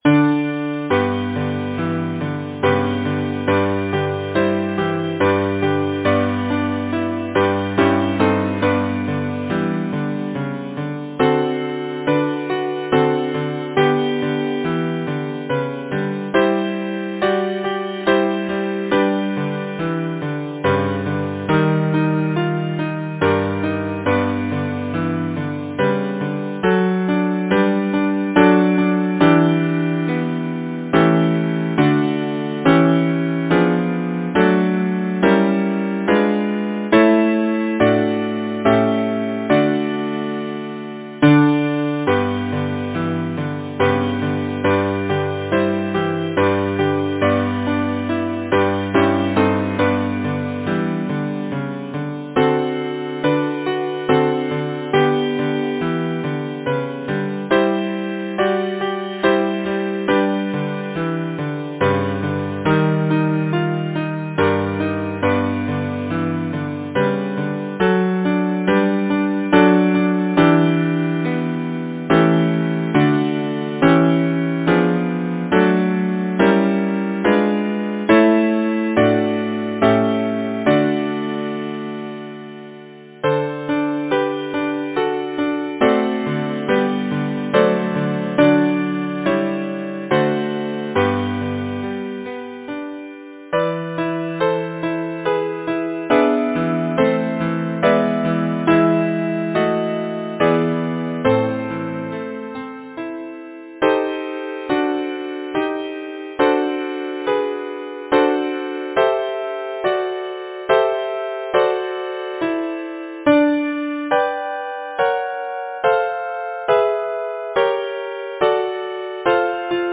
Title: How sweet the moonlight Composer: John George Callcott Lyricist: William Shakespeare Number of voices: 4vv Voicing: SATB, minor S divisi Genre: Secular, Partsong
Language: English Instruments: A cappella